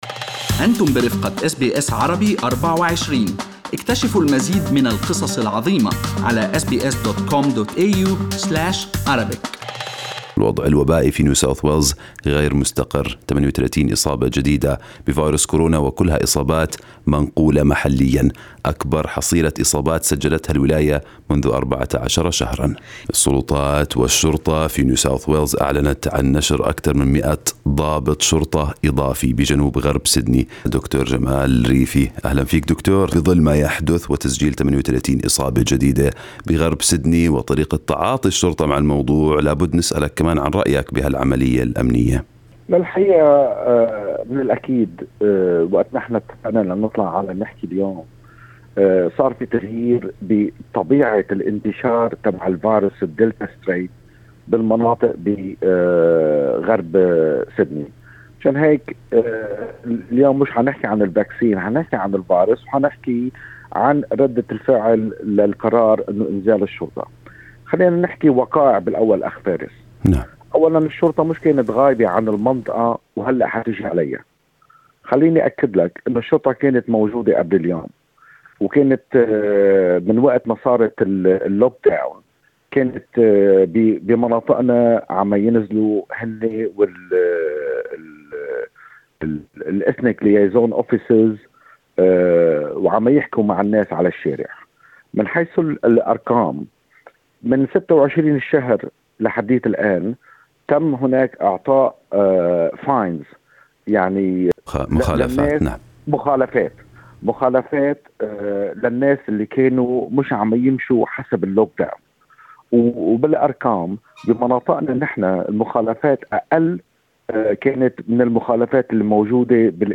يجيب على أسئلة مباشرة من أبناء الجالية العربية